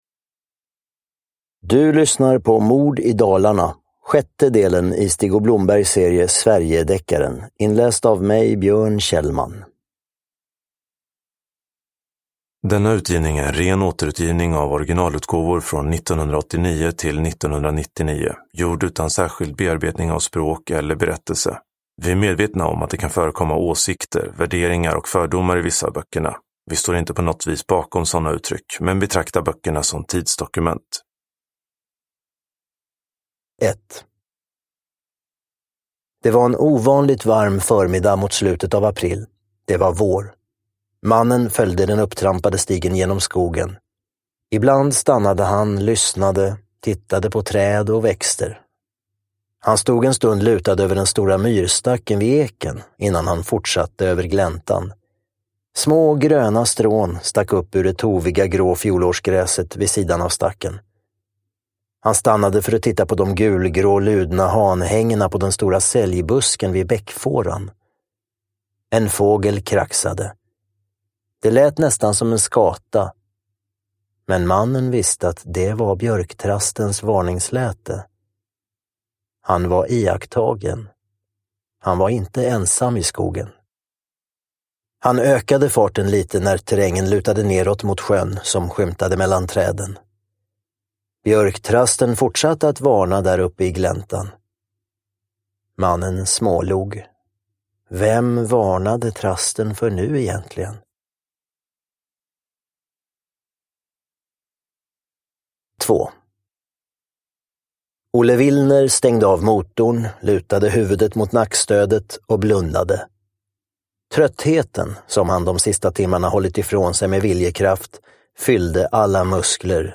Mord i Dalarna – Ljudbok
Uppläsare: Björn Kjellman